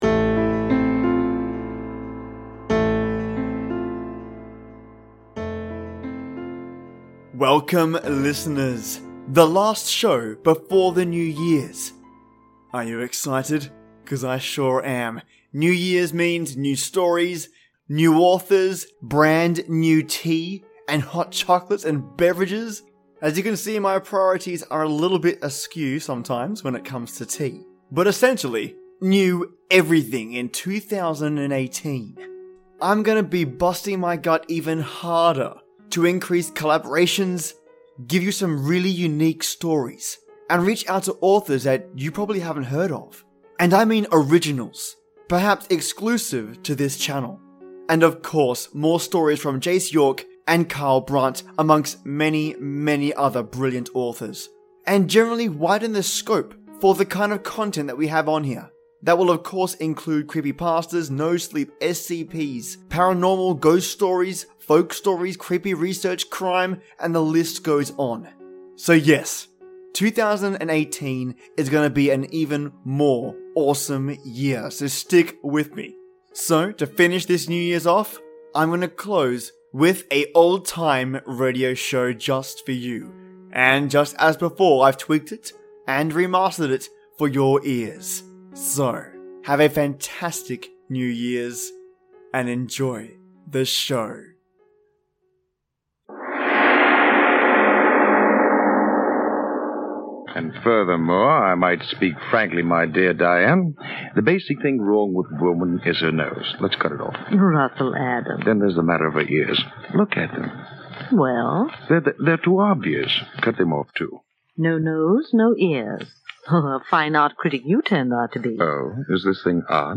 A meteor lands, is discovered, and...well....you just have to listen :P As always I've remastered / edited and tweaked the audio the best I currently can to help make it far more listenable.